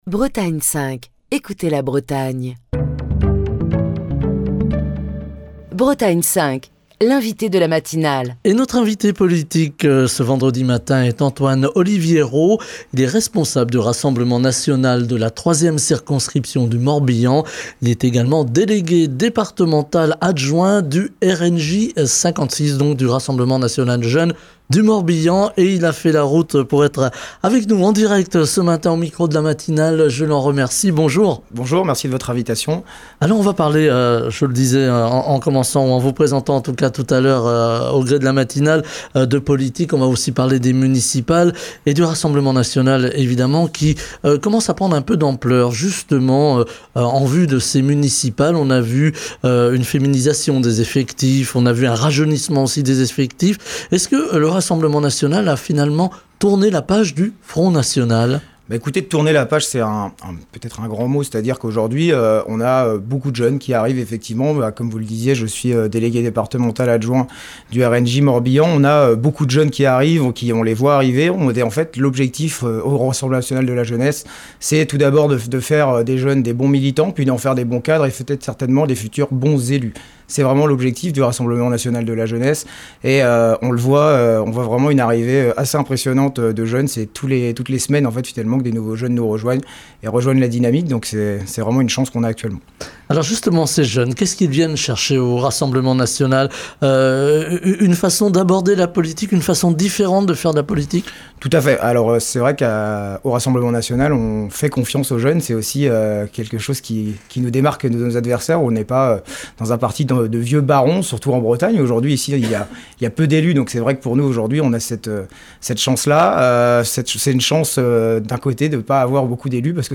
Émission du 19 décembre 2025.